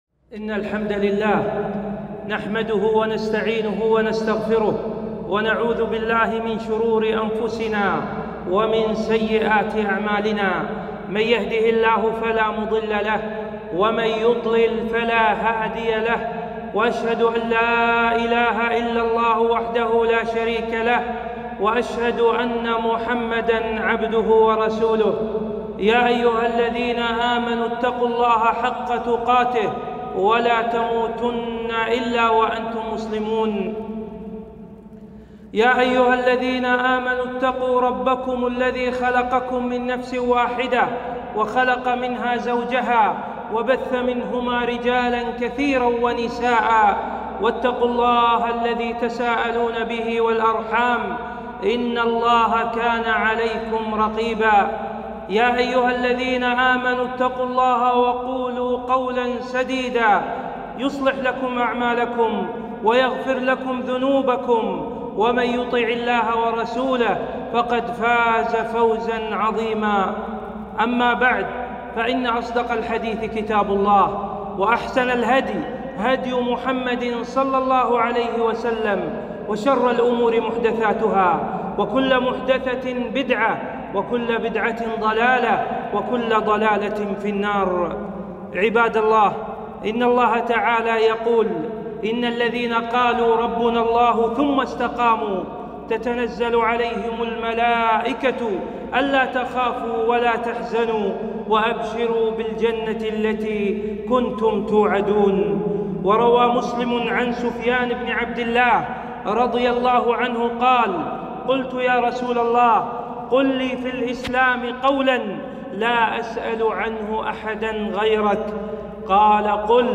خطبة - لذة الاستقامة وأسبابها - دروس الكويت